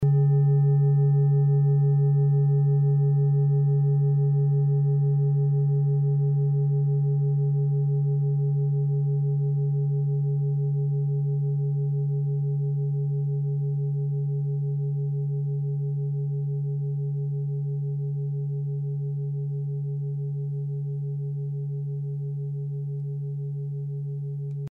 Klangschale TIBET Nr.26
Sie ist neu und ist gezielt nach altem 7-Metalle-Rezept in Handarbeit gezogen und gehämmert worden..
(Ermittelt mit dem Filzklöppel)
Der Marston liegt bei 144,72 Hz, das ist nahe beim "D".
klangschale-tibet-26.mp3